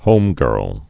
(hōmgûrl)